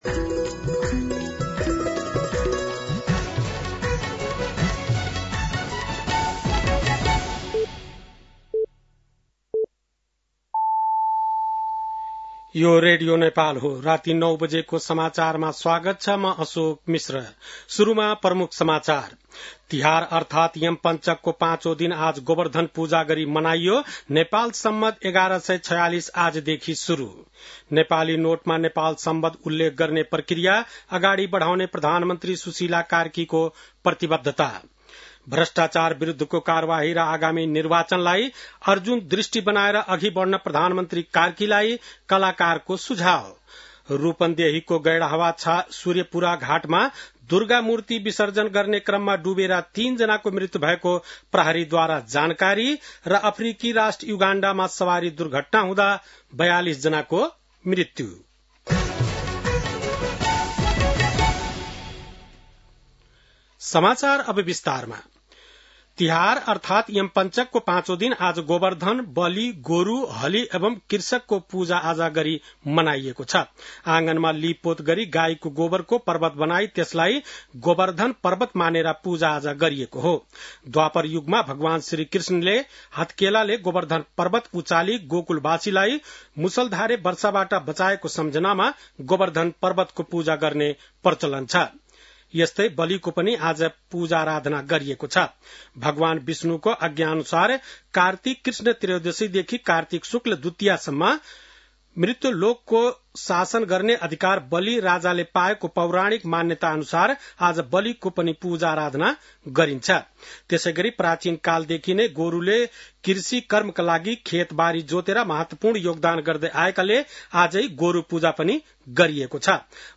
बेलुकी ९ बजेको नेपाली समाचार : ५ कार्तिक , २०८२